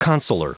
Prononciation du mot consular en anglais (fichier audio)
Prononciation du mot : consular